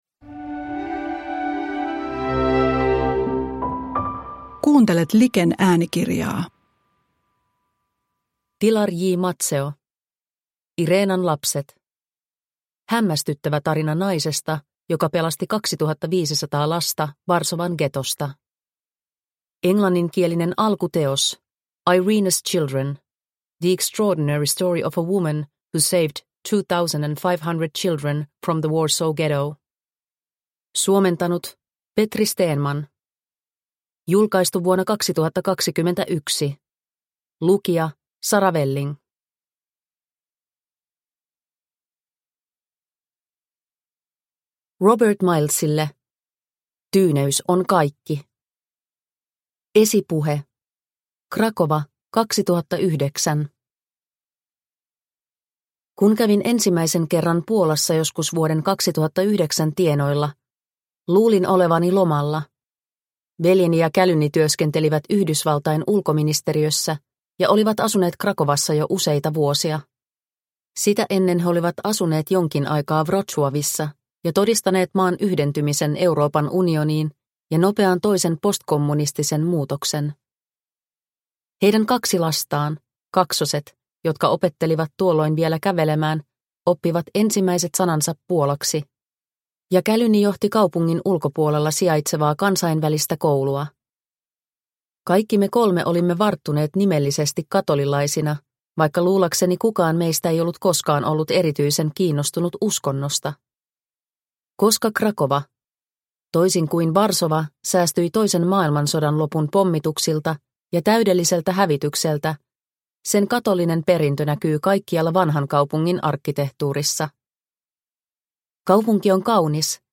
Irenan lapset – Ljudbok – Laddas ner